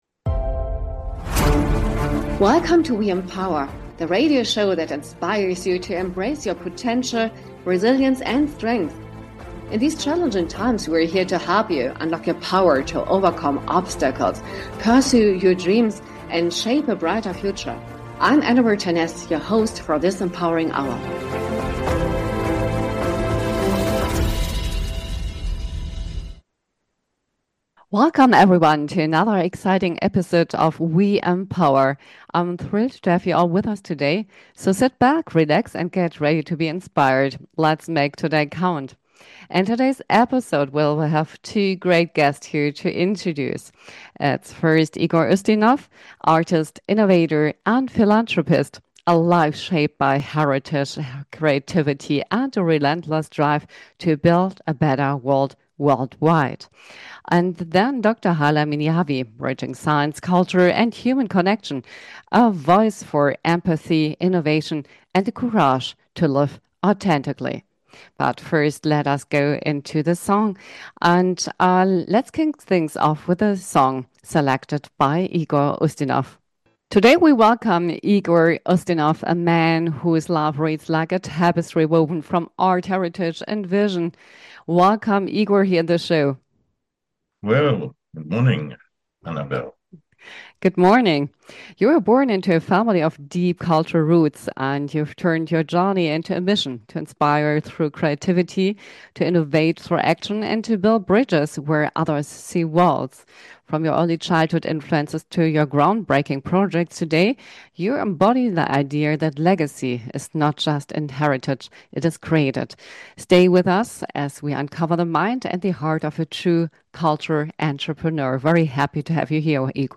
Welcome to "WE EMPOWER" – a radio show inspiring women to unleash their strengths and thrive in various life aspects. Featuring interviews with impressive female personalities across professions and discussions on women-led businesses, the show celebrates pioneers, especially female pioneers in history, science, art, and culture. It also explores self-development, mental health, and wellbeing, showcasing the most inspiriting books on these topics.